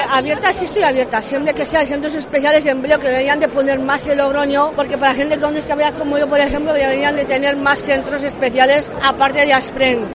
Jóvenes que también reivindican para ampliar sus opciones laborales en el ámbito de la discapacidad: